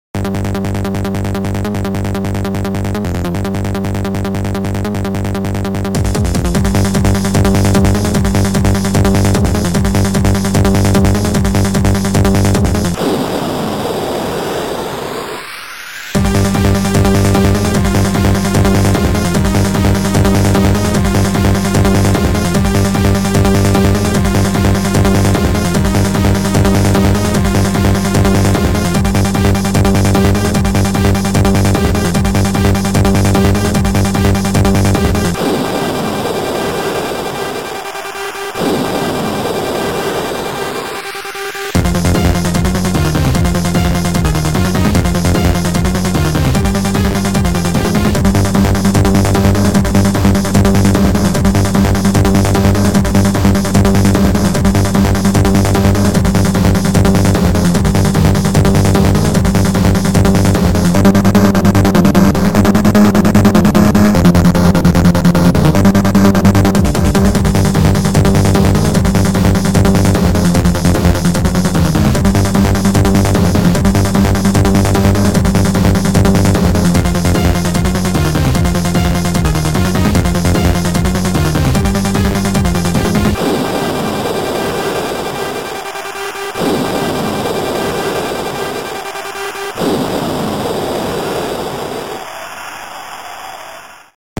Music ( Noisetracker/Protracker )